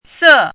注音： ㄙㄜˋ
se4.mp3